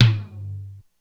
Index of /90_sSampleCDs/300 Drum Machines/Korg DSS-1/Drums01/03
LoTom.wav